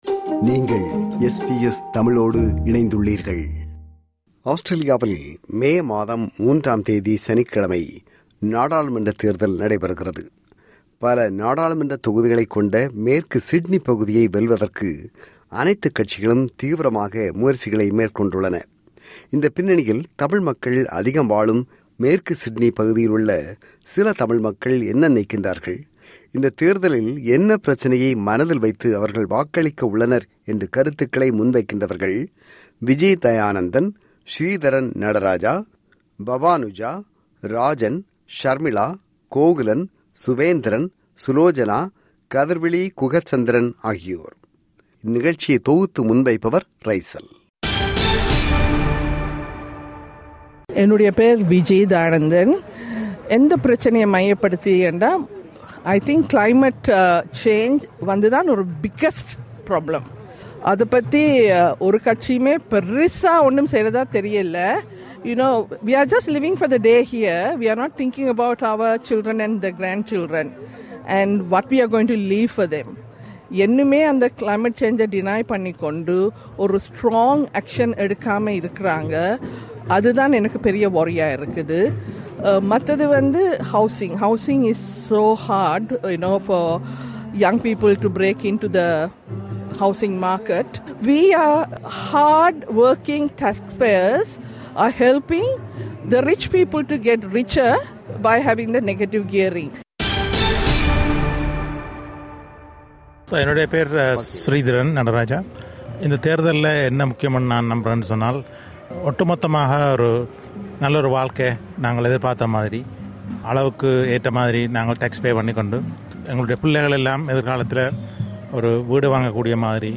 எங்கள் வாக்கு இதை பொறுத்துதான் இருக்கும் – சில தமிழ் வாக்காளர்கள்